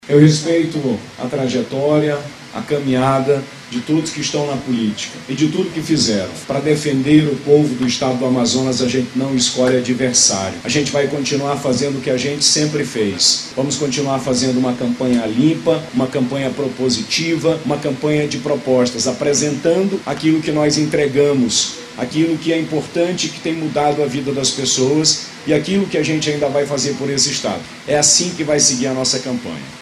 O atual governador do Amazonas que busca a reeleição, Wilson Lima, fez uma coletiva de imprensa logo após ser confirmado para o 2º turno. Ele agradeceu aos eleitores e reforçou que deve priorizar a transparência, caso seja eleito novamente.